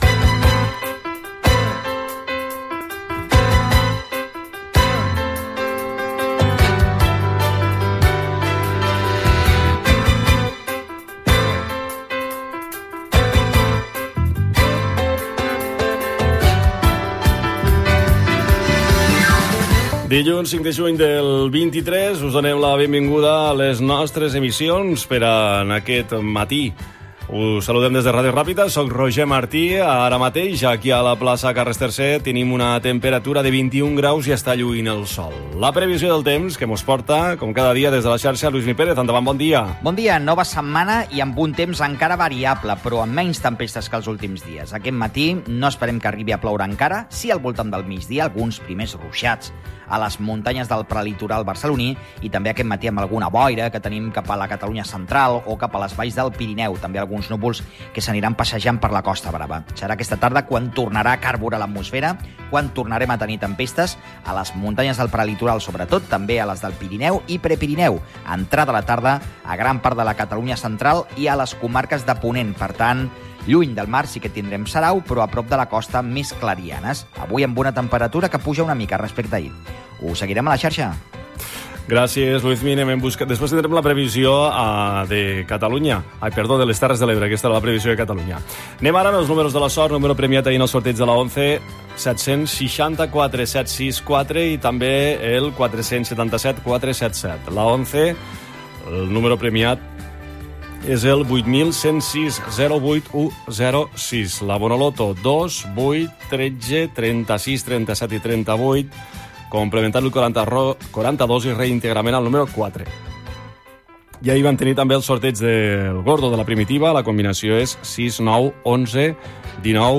Nom programa Bon dia i bona hora Gènere radiofònic Informatiu